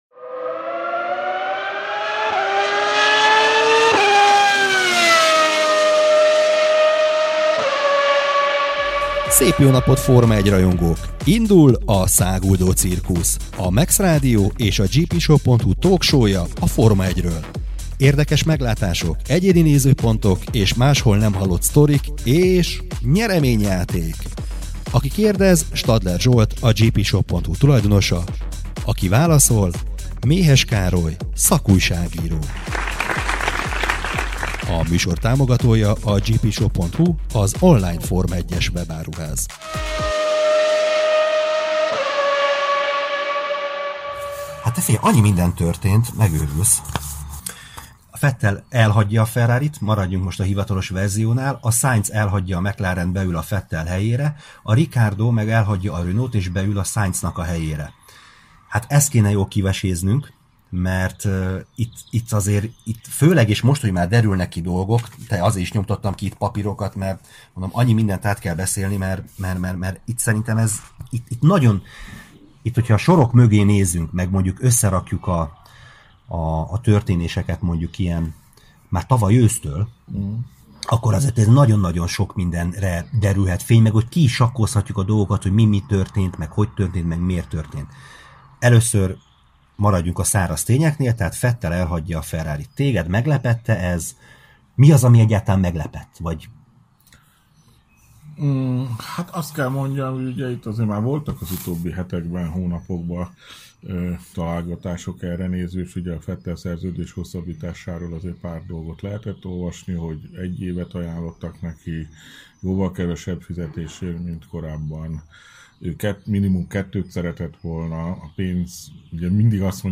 Forma 1-es talk shownk a Száguldó Cirkusz 2020 június 4-i adásának a felvétele a Mex Rádióból.